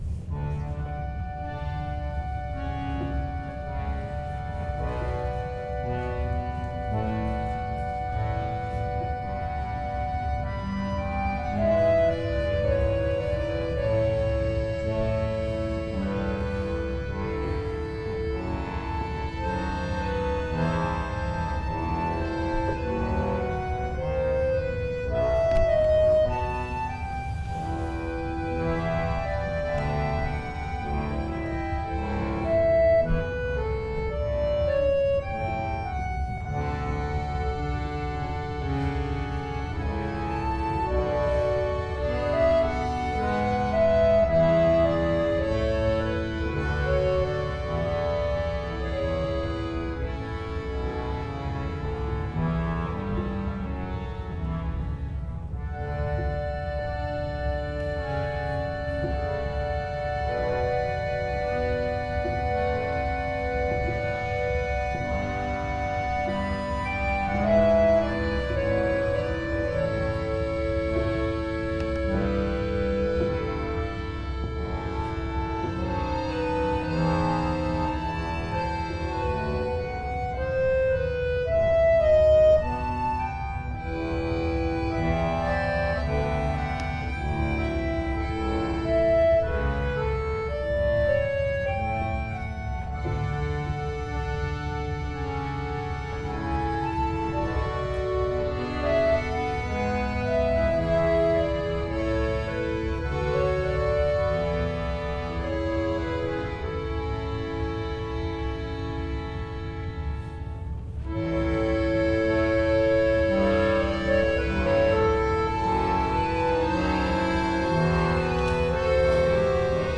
L’Ensemble d’accordéons du Val de Metz, ses juniors, et la Chorale luthérienne de Metz se sont associés pour composer ce programme du concert de l’Avent 2011.
Extraits du concert 3.
J.S. Bach, Aria de la Suite n°3 pour orchestre BWV 1068 , par l’Ensemble d’accordéons du Val de Metz